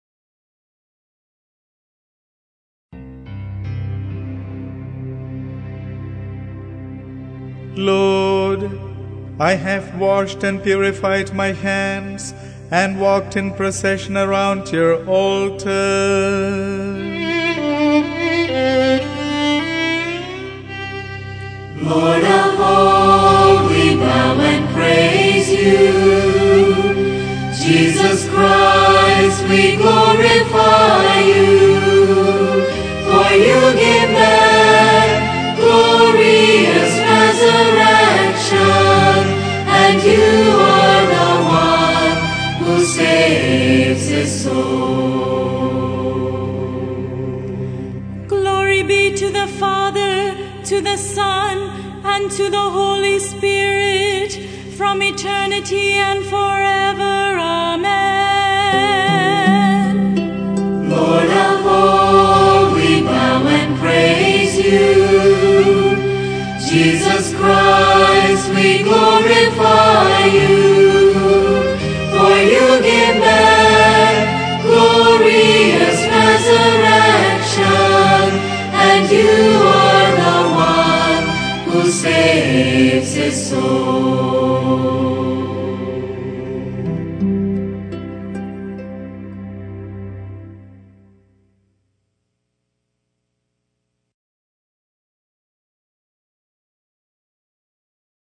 and chorus